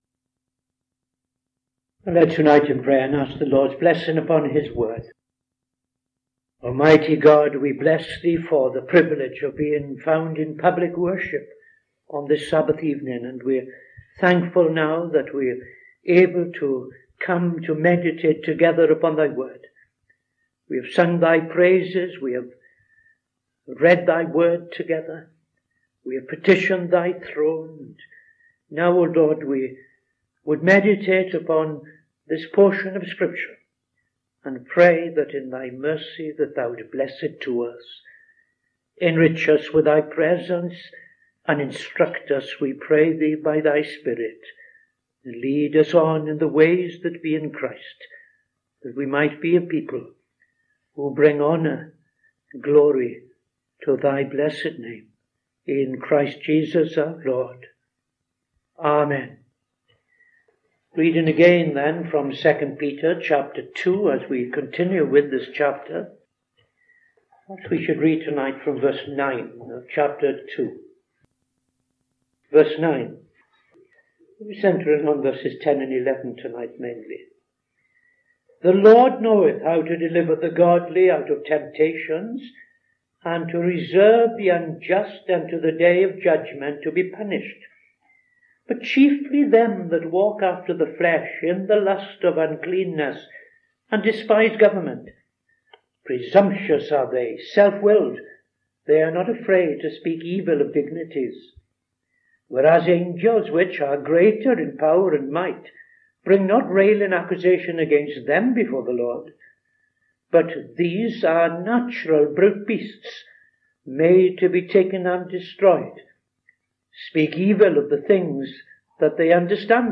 Opening Prayer and Reading II Peter 2:9-16